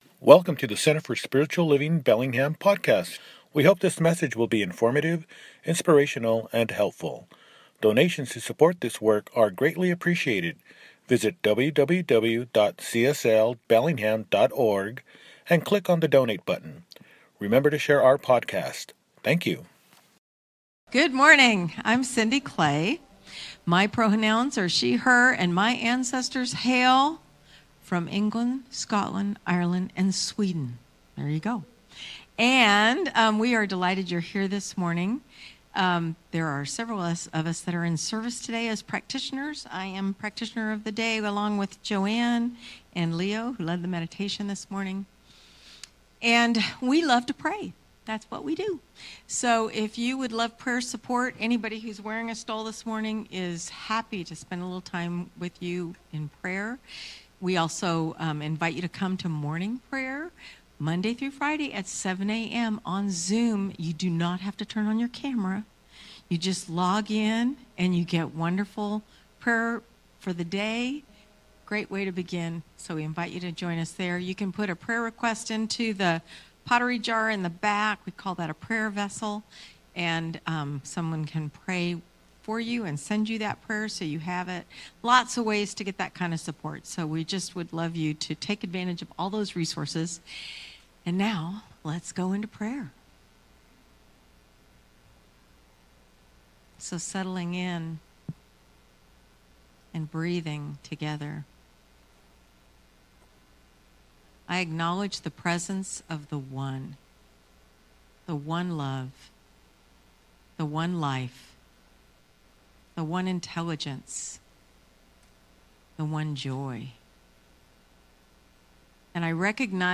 Inner Collective_ The Edge of the Known _ Celebration Service